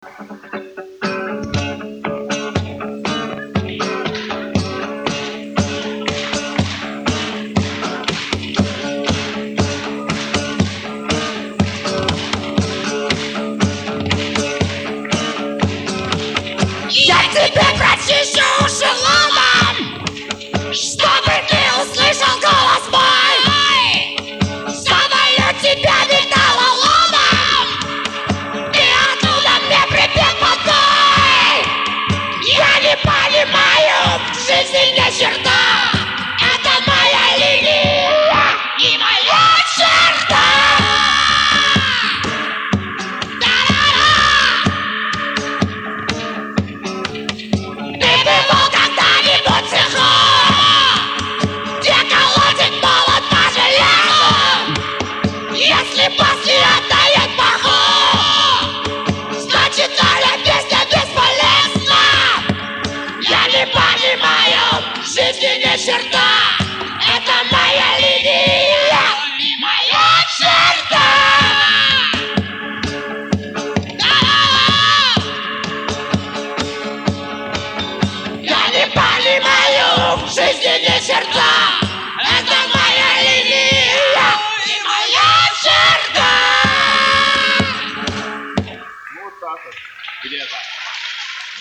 г.Псков (2000)